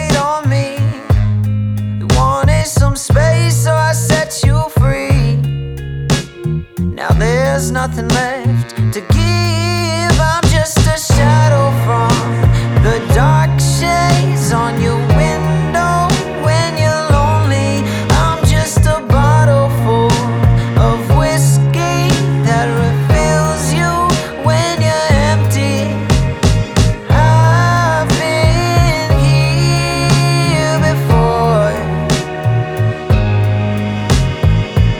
Жанр: Поп / R&b / Соул